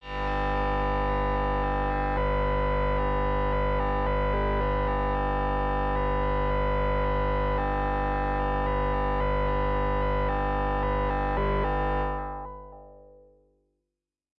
标签： MIDI-速度-57 FSharp4 MIDI音符-67 DSI-利 合成器 单票据 多重采样
声道立体声